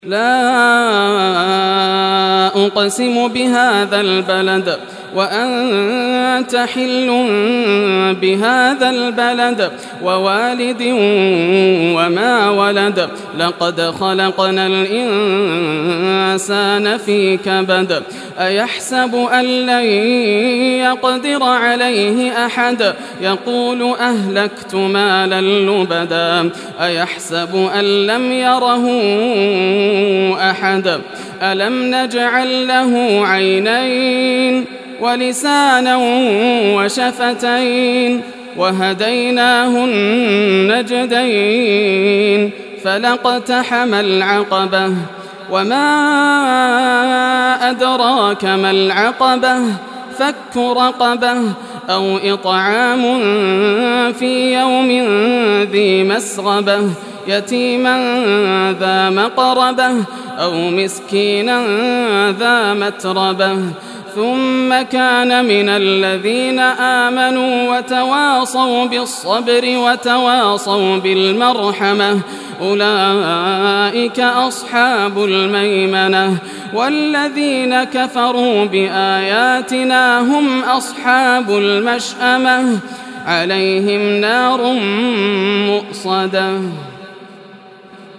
Surah Balad Recitation by Yasser al Dosari
Surah Balad, listen or play online mp3 tilawat / recitation in Arabic in the beautiful voice of Sheikh Yasser al Dosari.
90-surah-balad.mp3